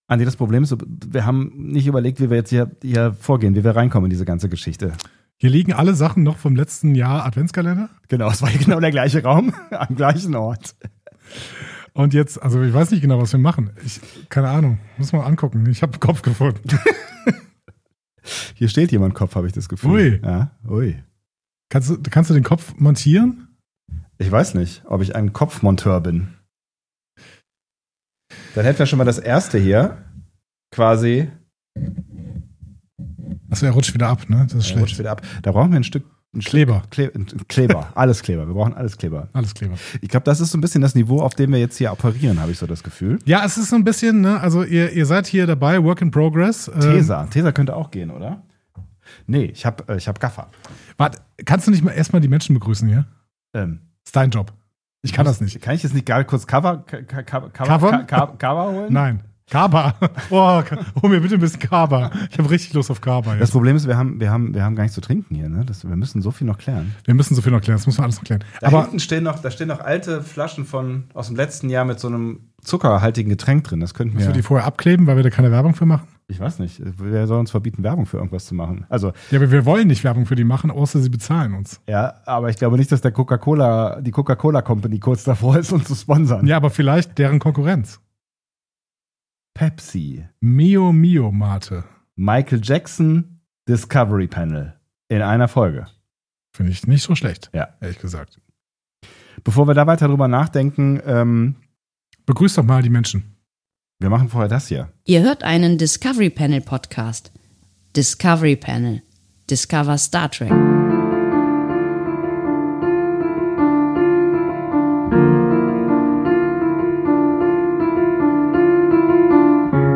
In dieser Folge kommen wir an – im Studio, in der Adventszeit und irgendwo zwischen ambitionierten Plänen und spontanen gedanklichen Seitensprüngen. Wir richten ein, wir räumen um, wir verlieren uns in Diskussionen und finden uns im besten Fall wieder.